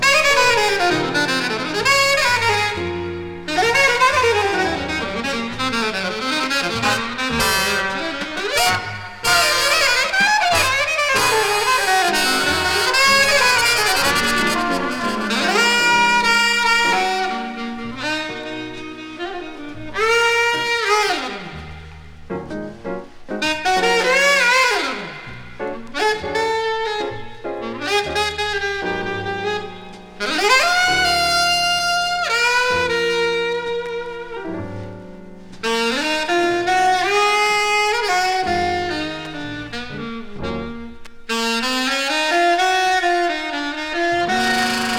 Jazz, Modal　USA　12inchレコード　33rpm　Stereo